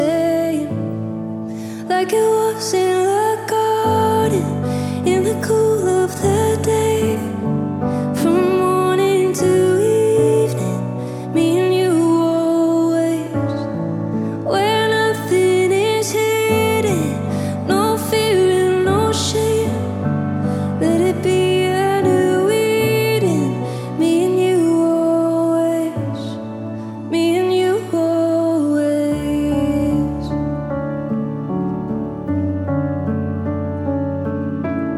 Christian